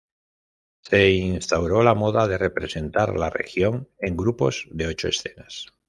Read more (feminine) fashion (feminine) fad (feminine) mode Frequency B2 Hyphenated as mo‧da Pronounced as (IPA) /ˈmoda/ Etymology Borrowed from French mode In summary Borrowed from French mode.